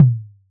edm-perc-08.wav